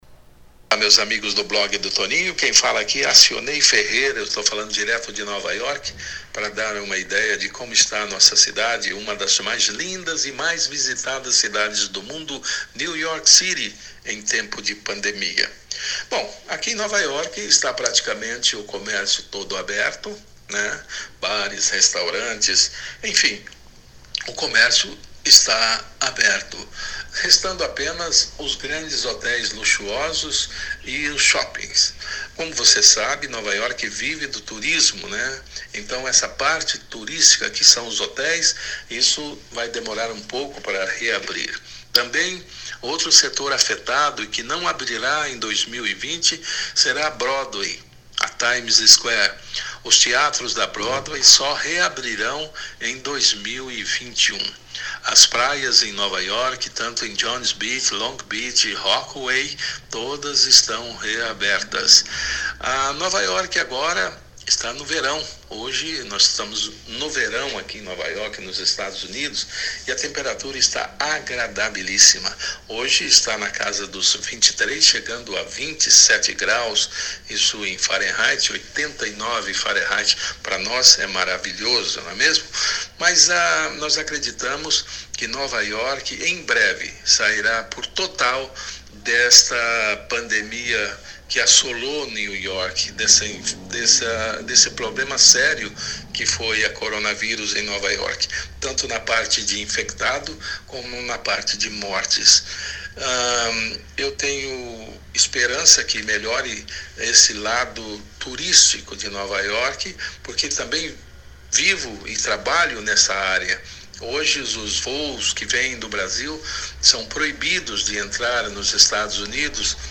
Ele passa um boletim informal para o nosso blog falando de como está Nova York neste início de julho.